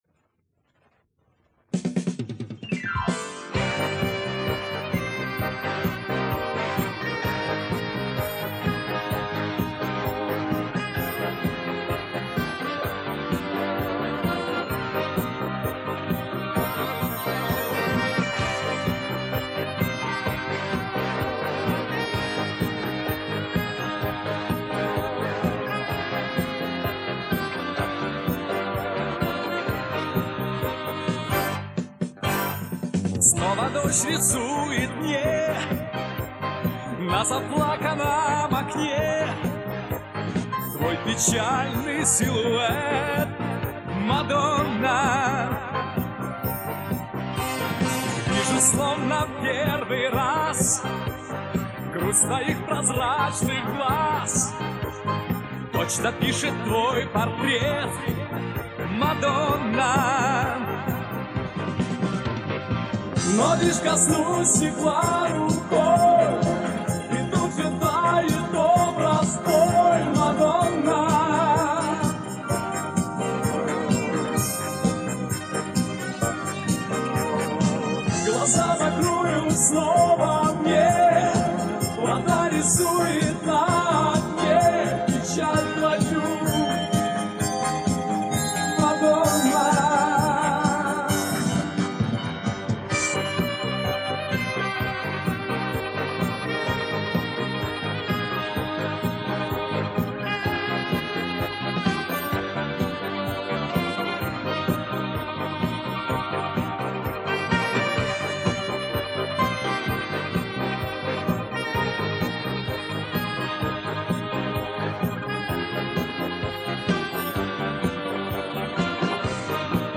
Странное звучание у этой песни.
Куплет и припев - разница в звучании.